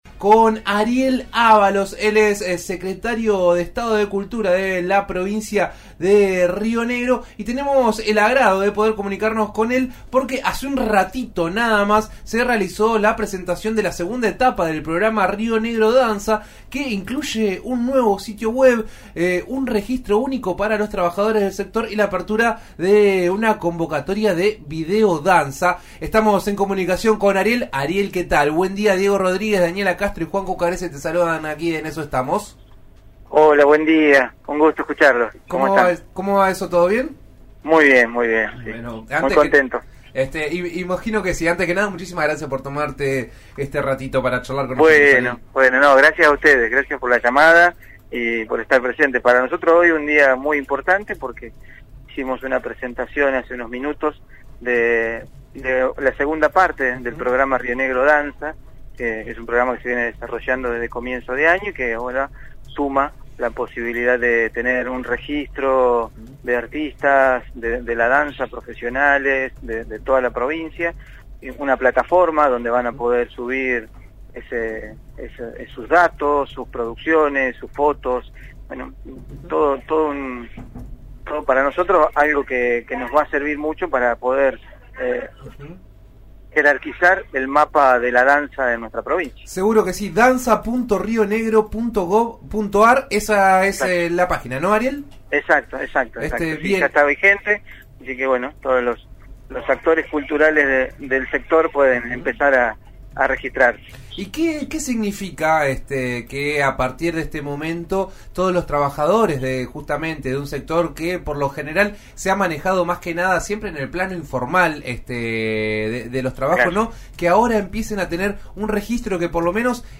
En eso estamos de RN Radio dialogó con Ariel Ávalos, Secretario de Estado de Cultura de Río Negro, sobre la segunda etapa del programa 'Río Negro Danza'.
En eso estamos de RN Radio (105.87 en Roca y 90.9 en Neuquén) dialogó con Ávalos sobre lo que significa la creación del registro de trabajadores del sector.